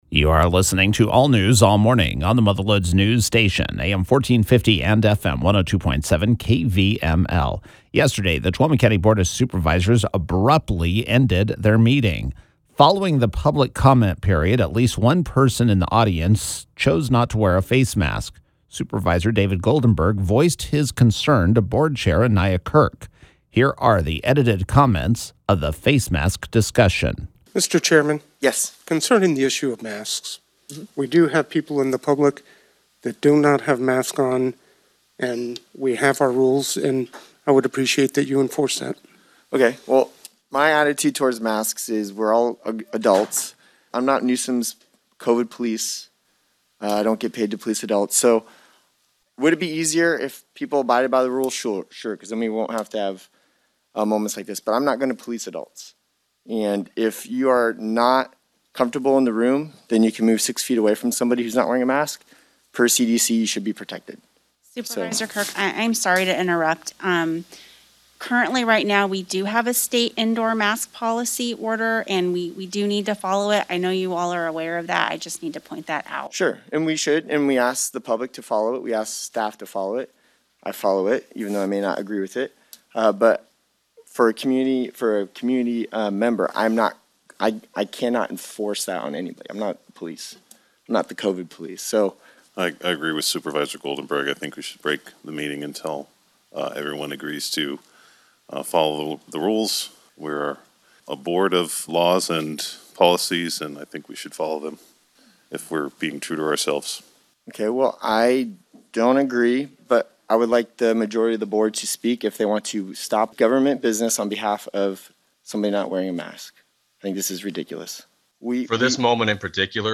Here are the edited remarks that were made during the meeting: